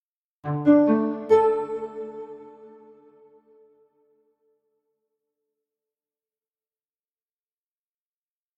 Мы отключились сами (успешное завершение)